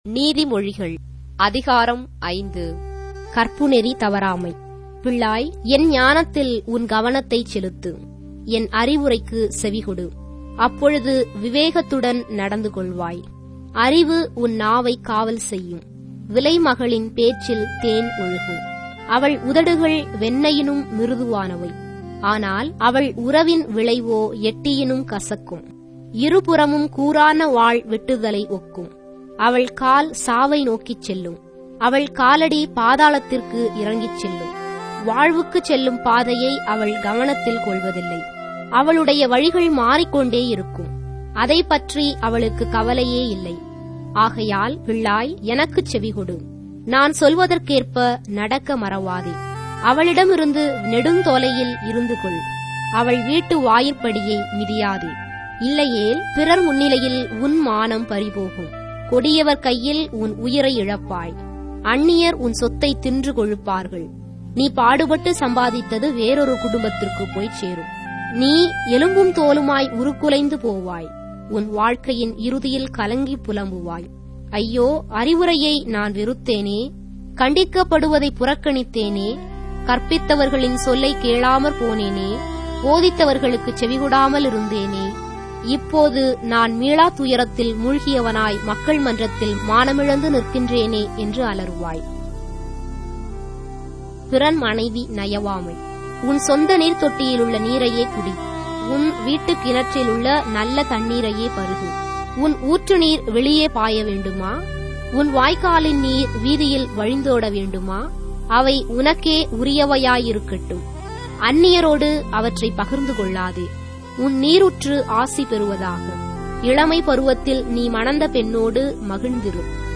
Tamil Audio Bible - Proverbs 5 in Ecta bible version